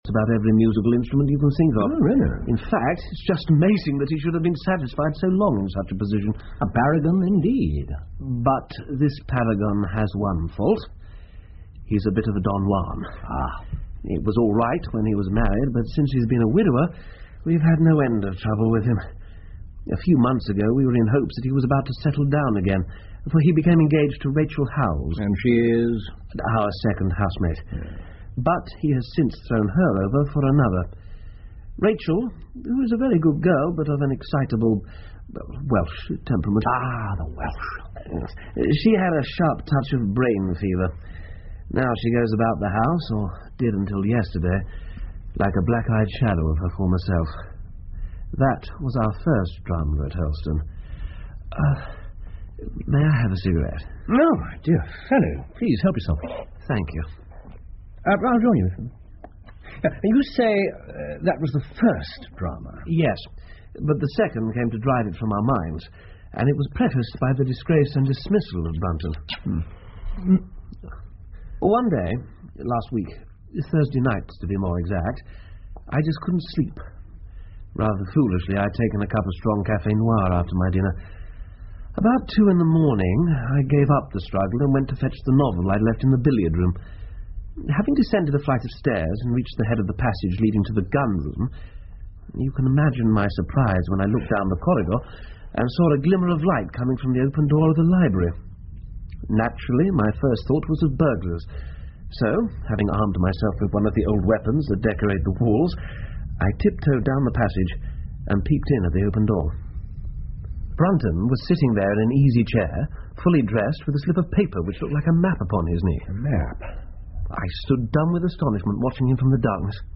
福尔摩斯广播剧 The Musgrave Ritual 3 听力文件下载—在线英语听力室